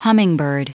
Prononciation du mot hummingbird en anglais (fichier audio)
Prononciation du mot : hummingbird